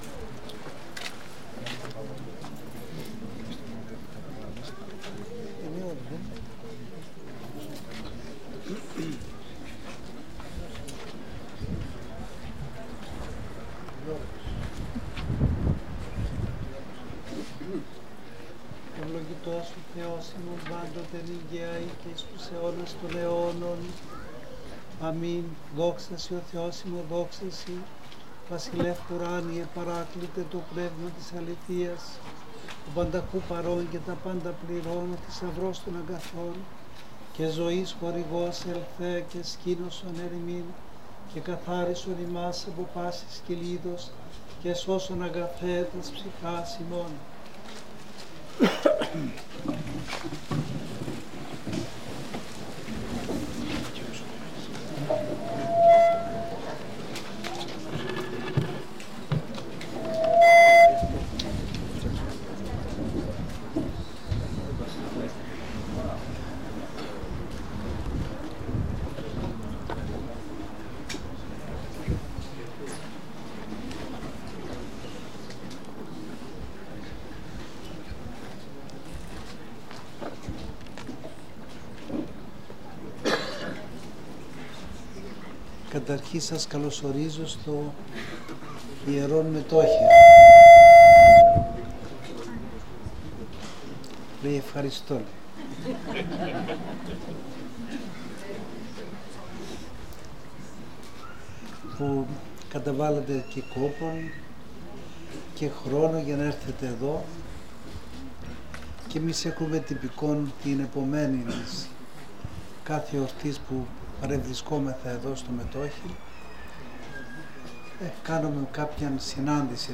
Μέσα σε κλίμα κατάνυξης και γαλήνης, πλήθος πιστών συγκεντρώθηκε, το Σάββατο 16 Αυγούστου 2025, στο Βατοπαιδινό Μετόχι στο Πόρτο Λάγος, συμμετέχοντας στις λατρευτικές εκδηλώσεις της εορτής της Κοιμήσεως της Θεοτόκου.
Κεντρικό σημείο της ημέρας αποτέλεσε η ομιλία του Καθηγουμένου της Ιεράς Μεγίστης Μονής Βατοπαιδίου, Γέροντος Εφραίμ , ο οποίος με λόγο μεστό και πατρικό μίλησε για τη σημασία της Παναγίας στη ζωή του χριστιανού. Ο Γέροντας ανέδειξε τη Μητέρα του Θεού ως καταφύγιο στις δυσκολίες και ως πηγή ελπίδας και ενίσχυσης για κάθε ψυχή που αγωνίζεται. Μετά την ομιλία ακολούθησε ένας ζωντανός διάλογος: οι πιστοί έθεσαν ερωτήματα, κατέθεσαν τις αγωνίες τους και μοιράστηκαν σκέψεις με τον Γέροντα Εφραίμ. Εκείνος, με πραότητα και απλότητα, απαντούσε δίνοντας λόγους παρηγορίας και ειρήνης.